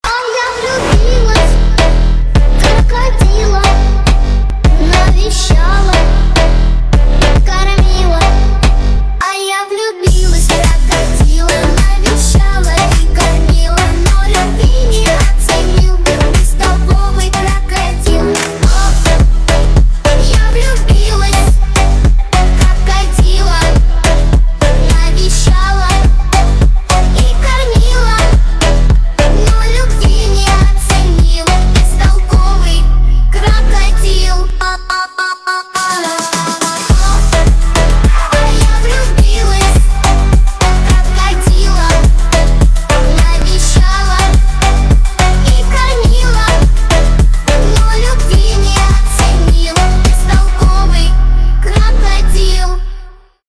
Веселые рингтоны
Фонк
клубные , детский голос